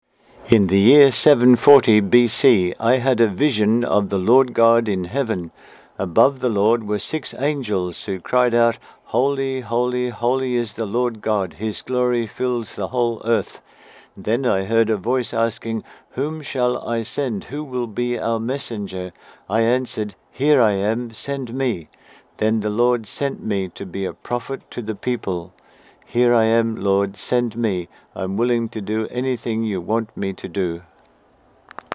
My recording of this reading